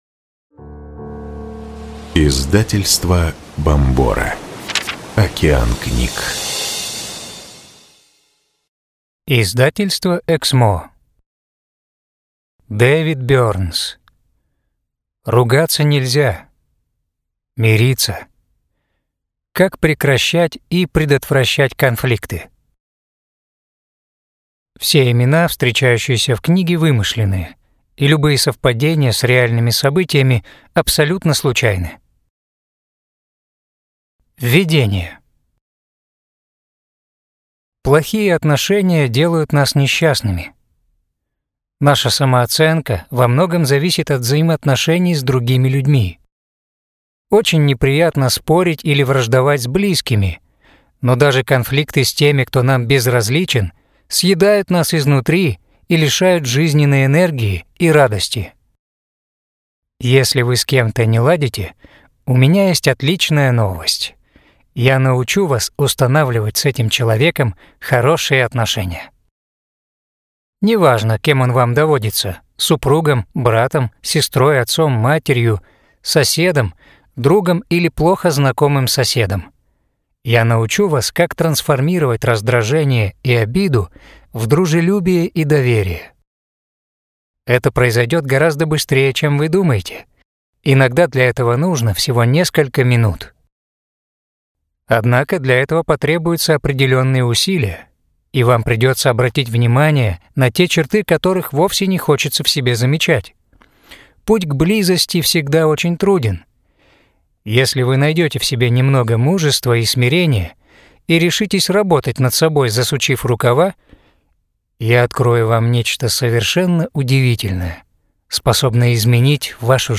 Аудиокнига Ругаться нельзя мириться. Как прекращать и предотвращать конфликты | Библиотека аудиокниг
Прослушать и бесплатно скачать фрагмент аудиокниги